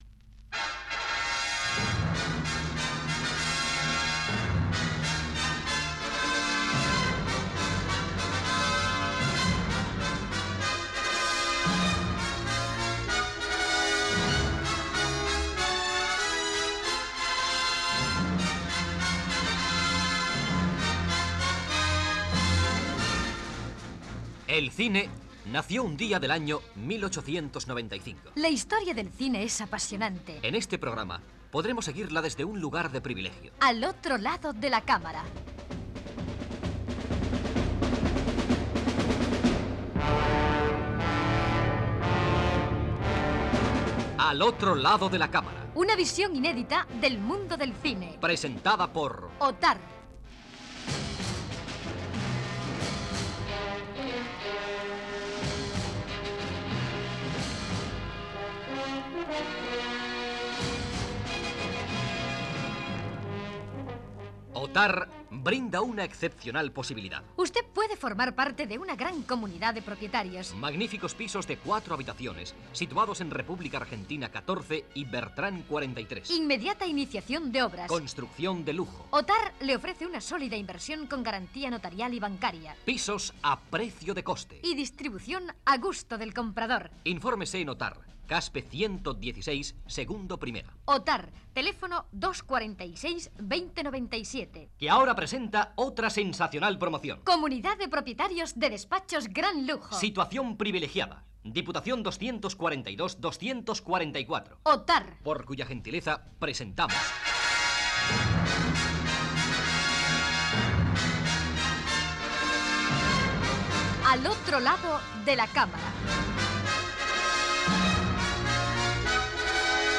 Careta del programa i primers moments dedicats a Charles Chaplin
Cultura